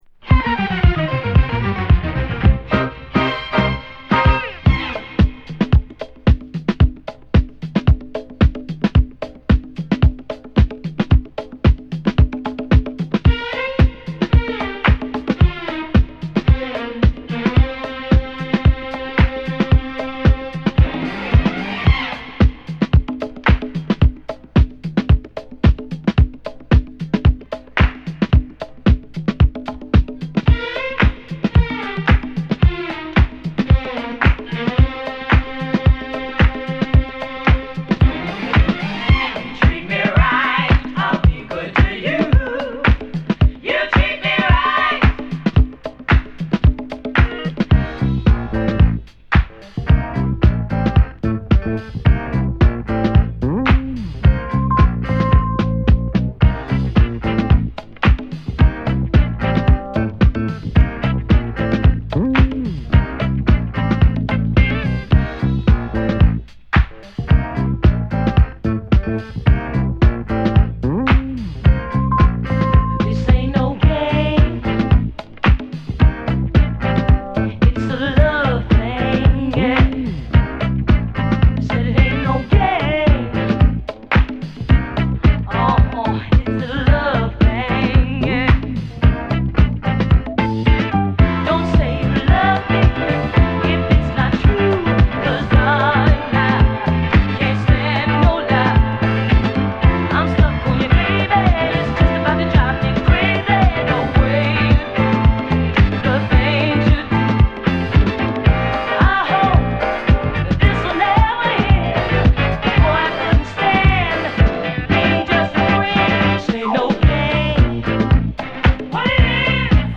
フィラデルフィア出身のヴォーカルトリオ